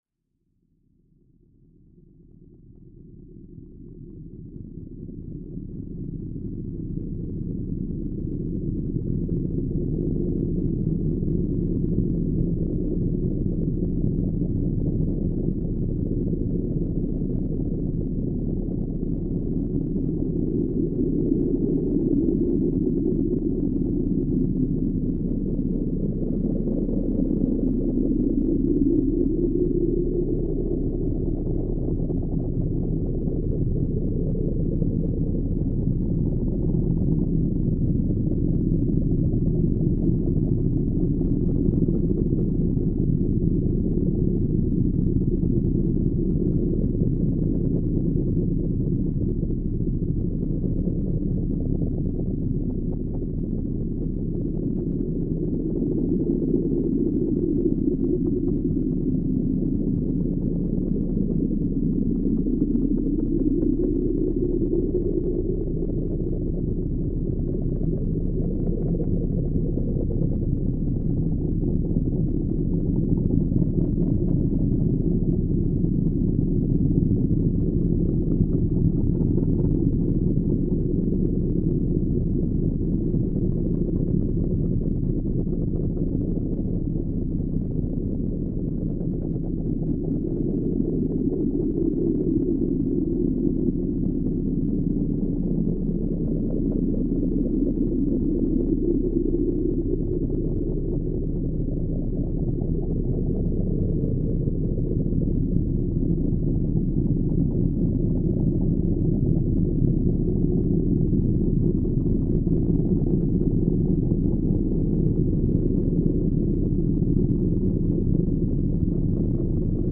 Austere but sonically dense.